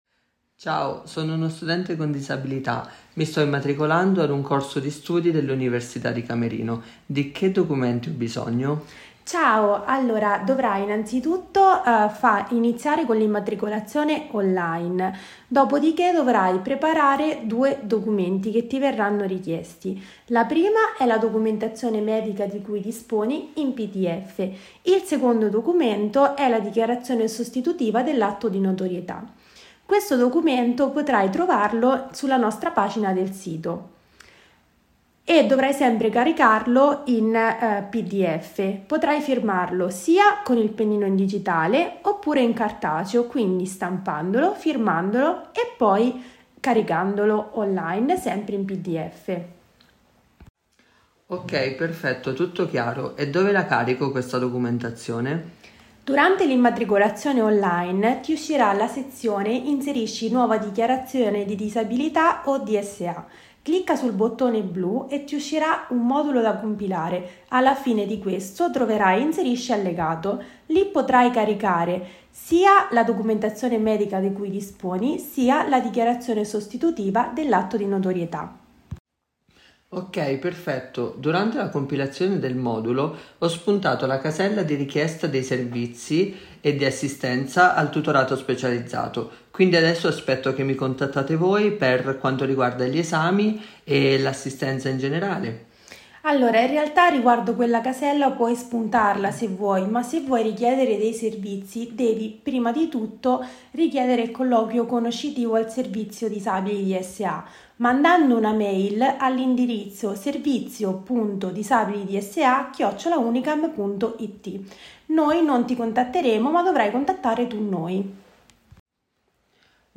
Audiotutorial | Trascrizione